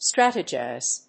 発音記号
• / ˈstrætʌdʒaɪz(米国英語)